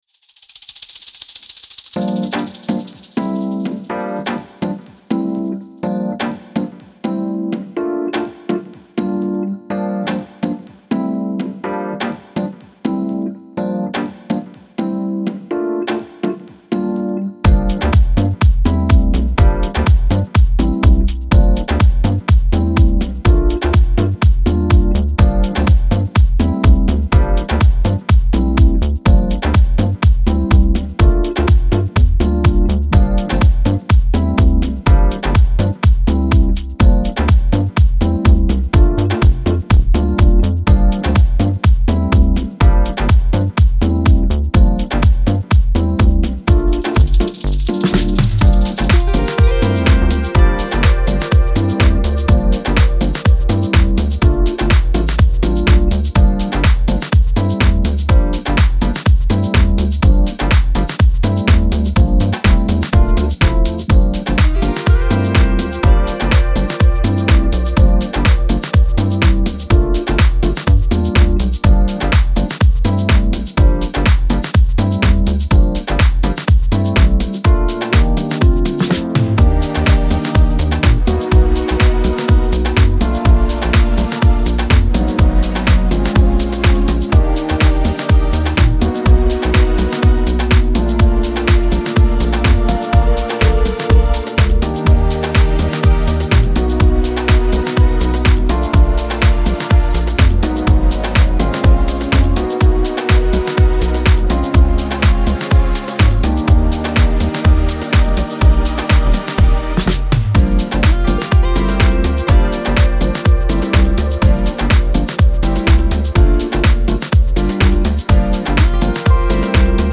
124 Soulful House